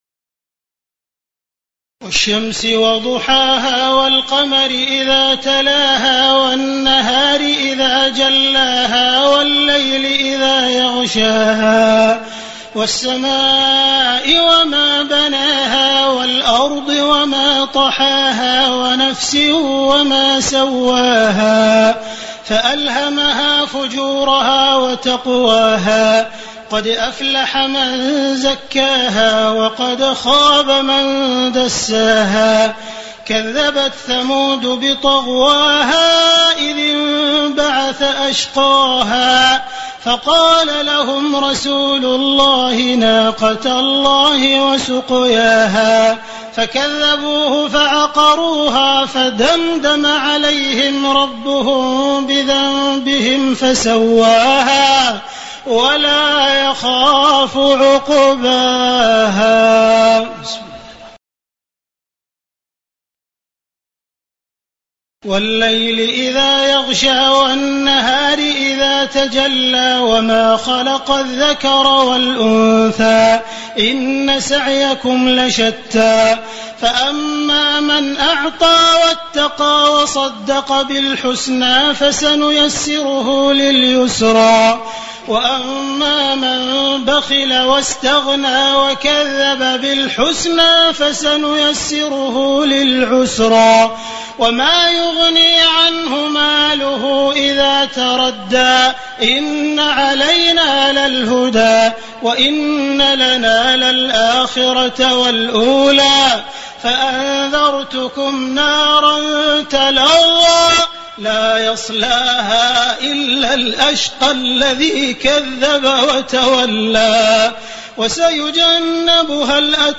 تراويح ليلة 29 رمضان 1429هـ من سورة الشمس الى الناس Taraweeh 29 st night Ramadan 1429H from Surah Ash-Shams to An-Naas > تراويح الحرم المكي عام 1429 🕋 > التراويح - تلاوات الحرمين